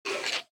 Minecraft Version Minecraft Version snapshot Latest Release | Latest Snapshot snapshot / assets / minecraft / sounds / mob / stray / idle3.ogg Compare With Compare With Latest Release | Latest Snapshot